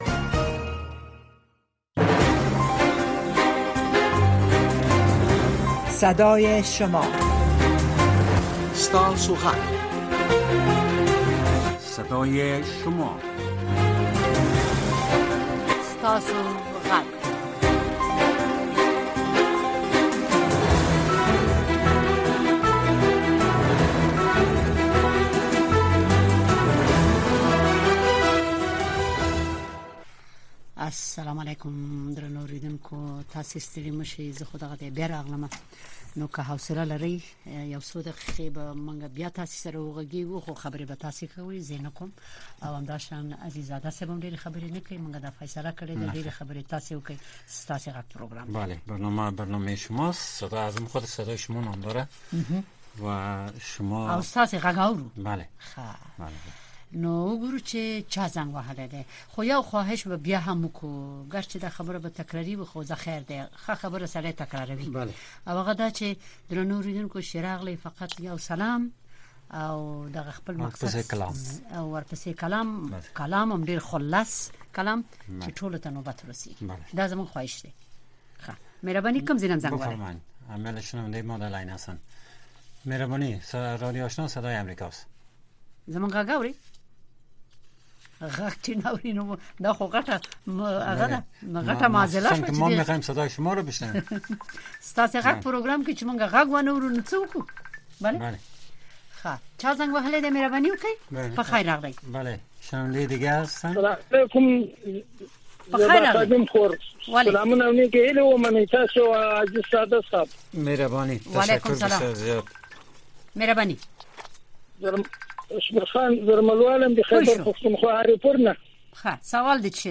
این برنامه به گونۀ زنده از ساعت ۱۰:۰۰ تا ۱۰:۳۰ شب به وقت افغانستان نشر می‌شود.